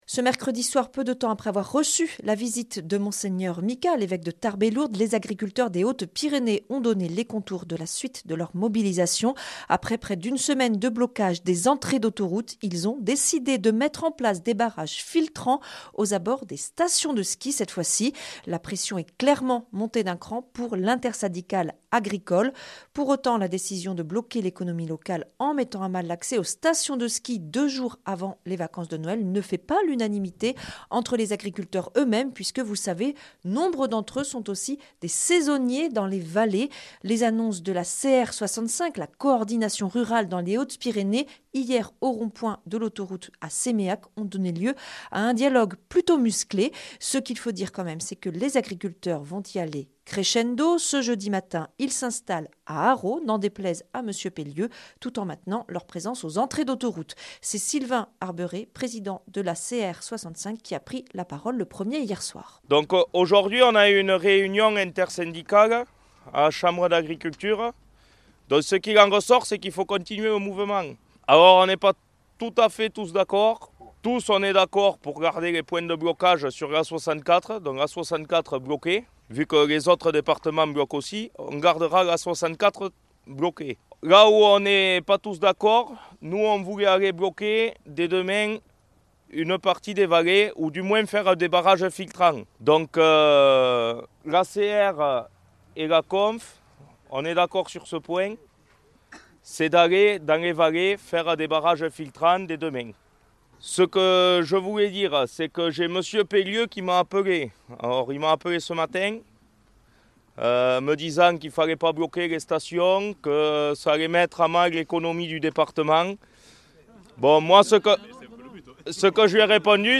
jeudi 18 décembre 2025 Interview et reportage Durée 10 min
La crise agricole monte d'un cran, reportage dans les Hautes-Pyrénées.